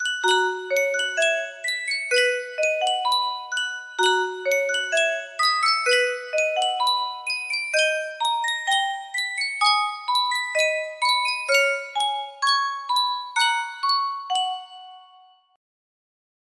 Yunsheng Music Box - My Gal Sal 5927 music box melody
Full range 60